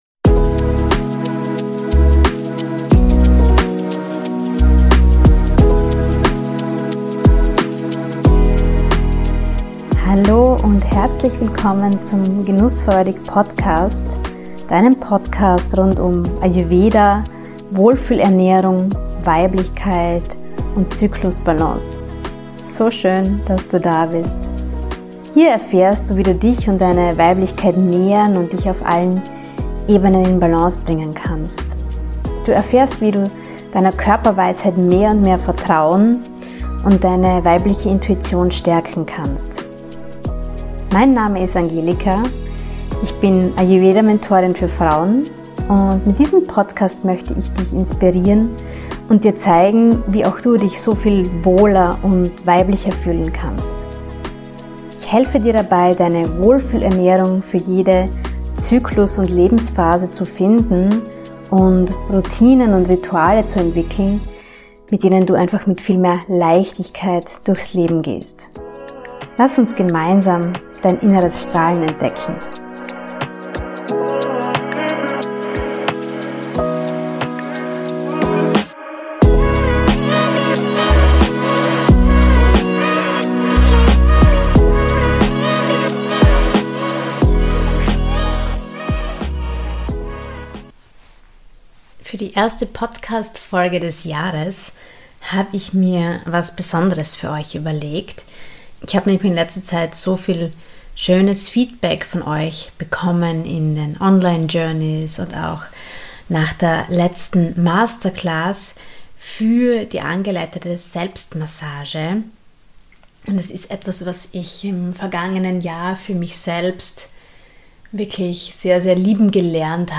Angeleiteter Marma-Selbstmassage